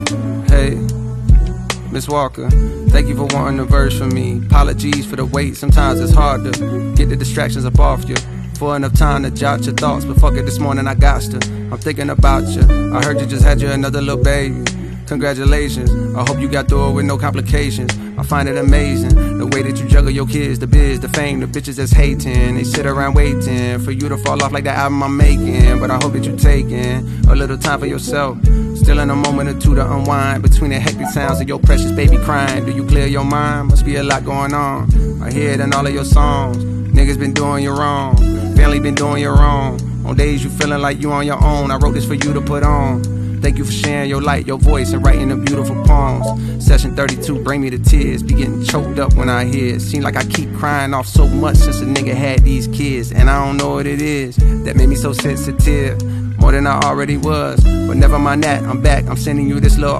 songs that you can sit back and relax to!